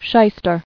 [shy·ster]